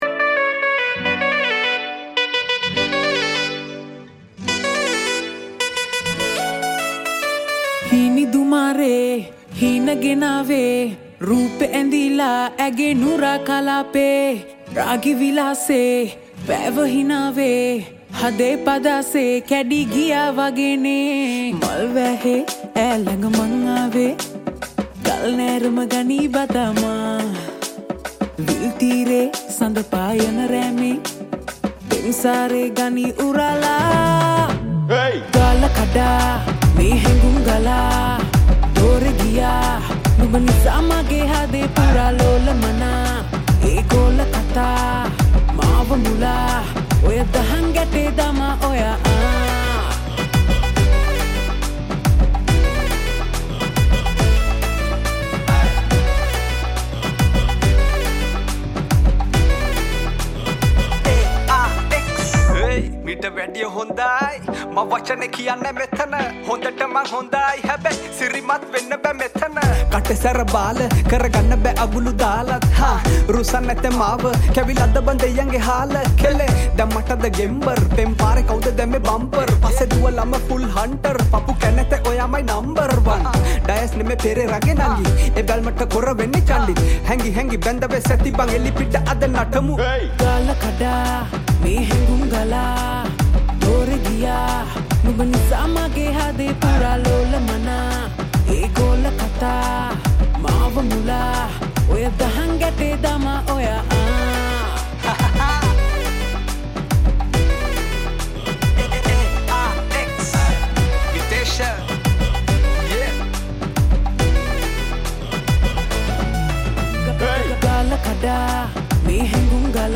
Rap Lyrics